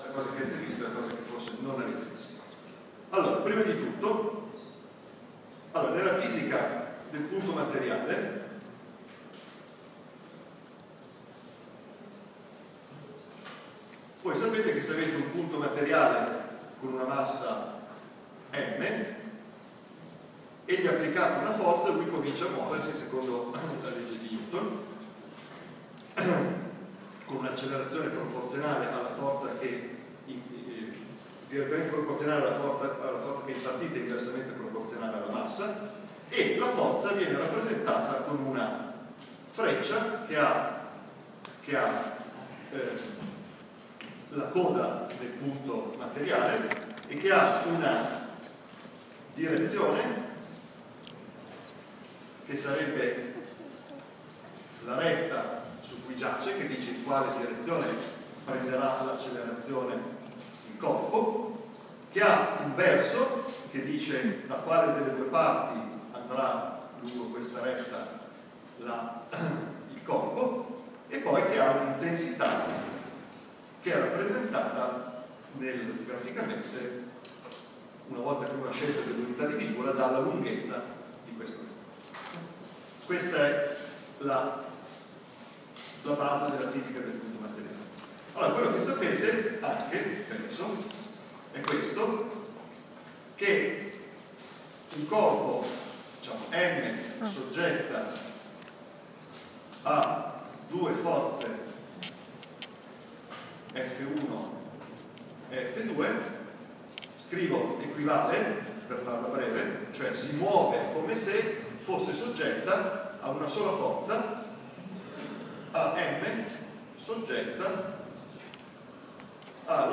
Lezioni